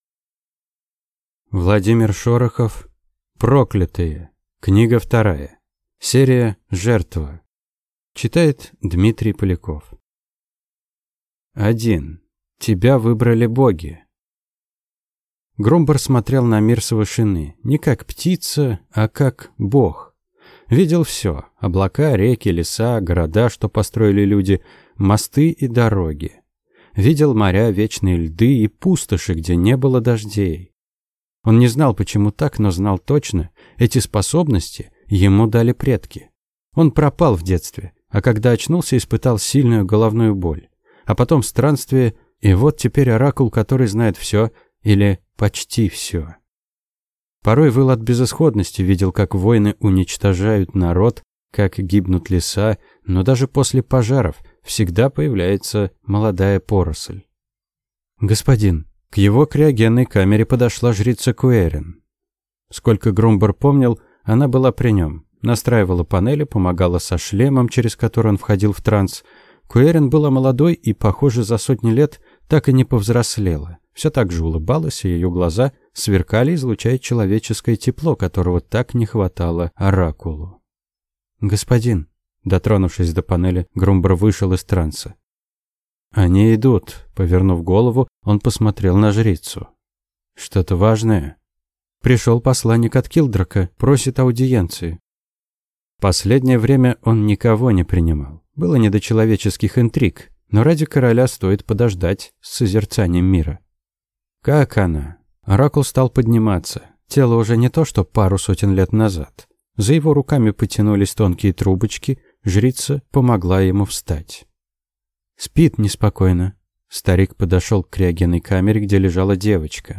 Аудиокнига Проклятые. Книга 2 | Библиотека аудиокниг